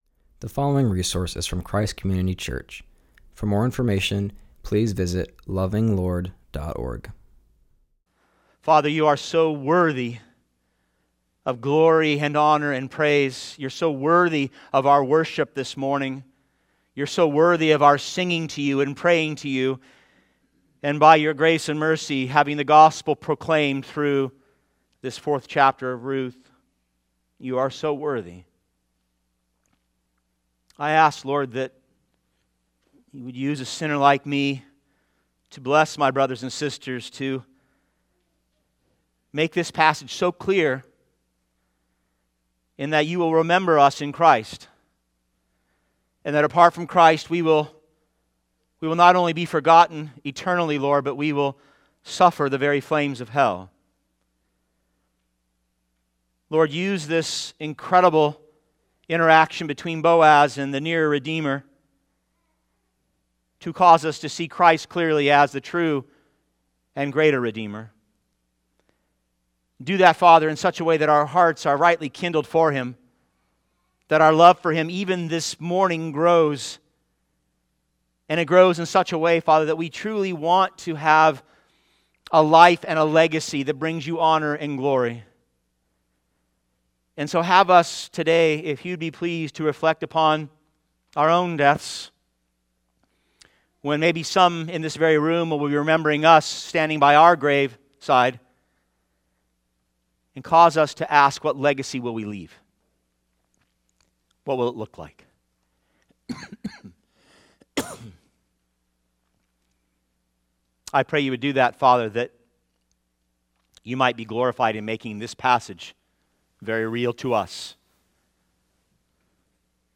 continues our series and preaches on Ruth 4:1-8